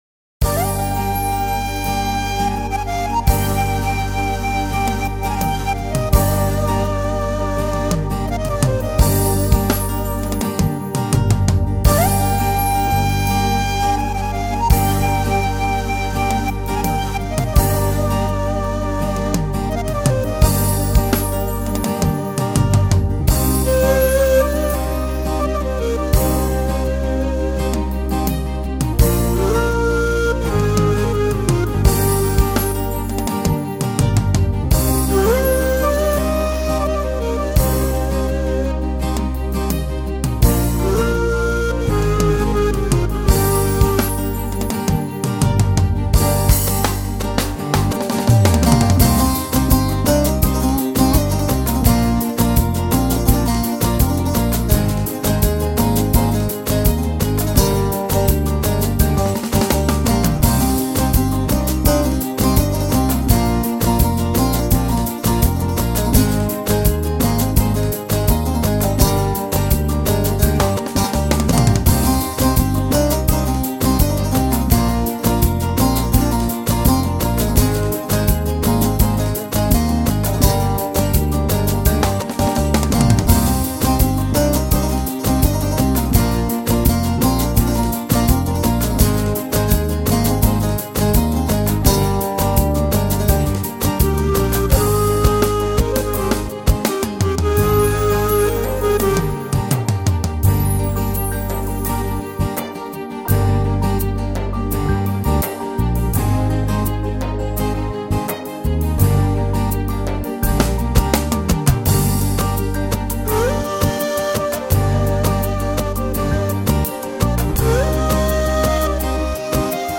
Selam arkadaşlar A2000 ile acizane çalmaya çalıştım. USB recorderden direk wav kayıt.